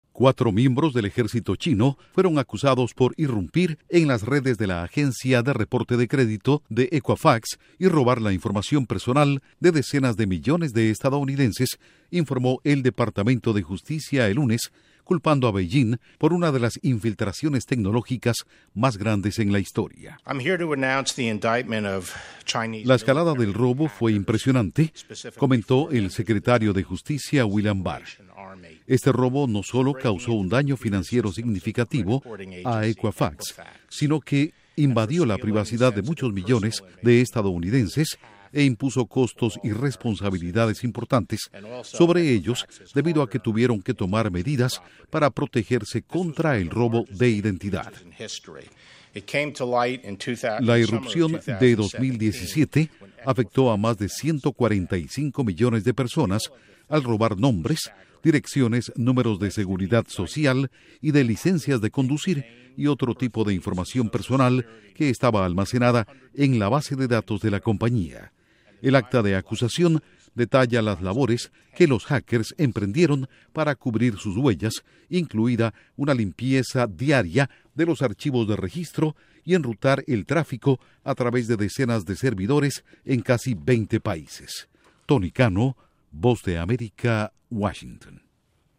Duración: 1:28 Con declaraciones de William Barr/Secretario de Justicia